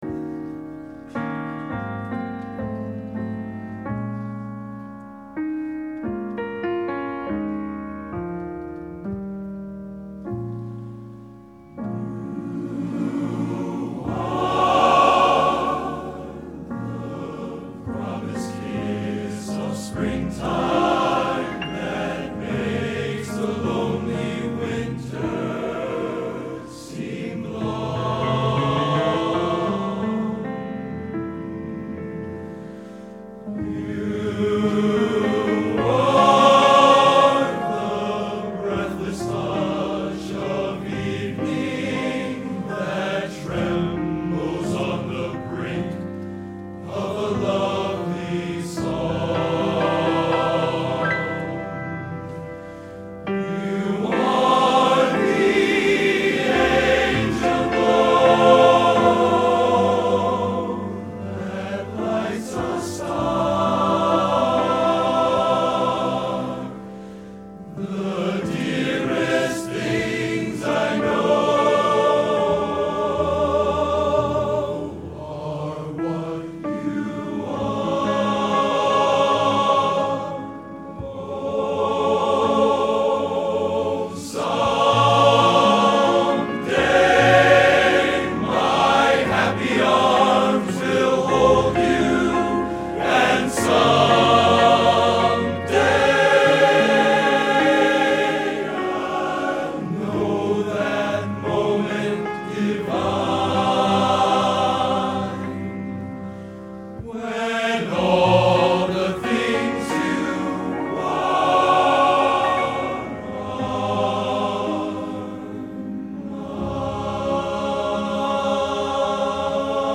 Genre: Schmalz | Type: